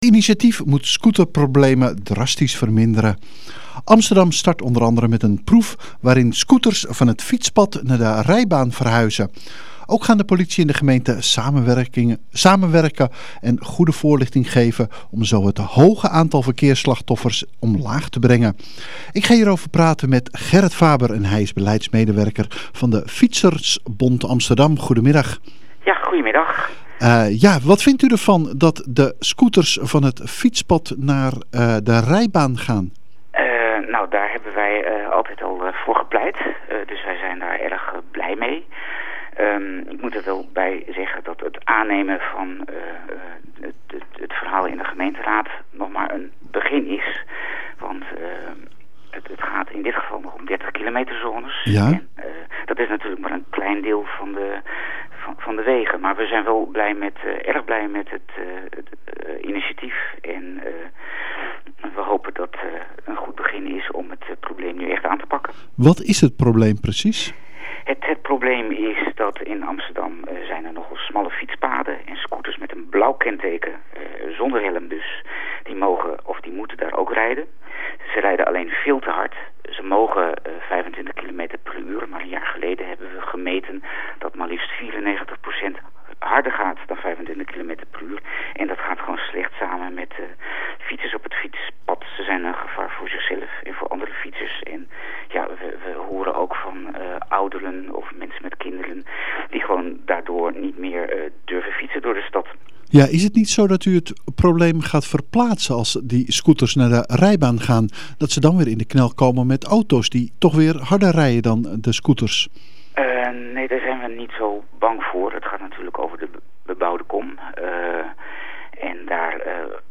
Hij gaf zijn commentaar op het aanstaande experiment met snorscooters én welke initiatieven wij van hen de komende tijd kunnen verwachten.